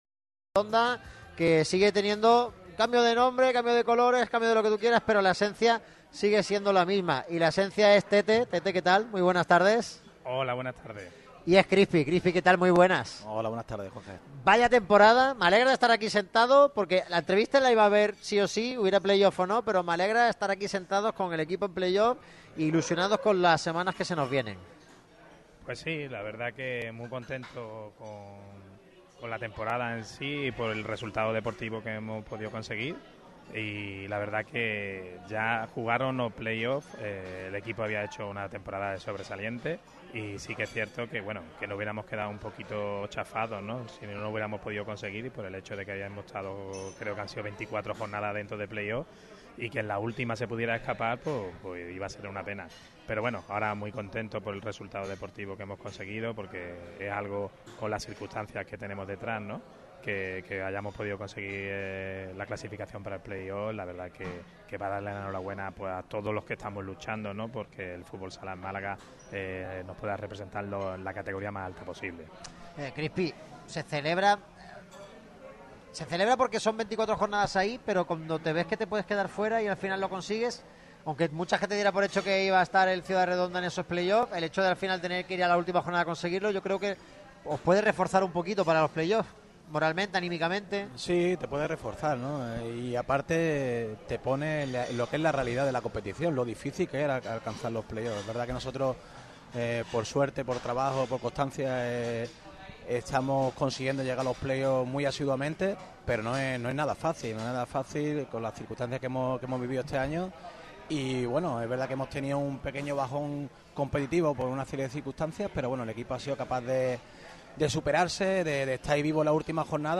Entrevista
El equipo de Radio MARCA Málaga ha tenido hoy el placer de visitar el restaurante Casa Juan Los Mellizos, ubicados en Calle de San Ginés, 20.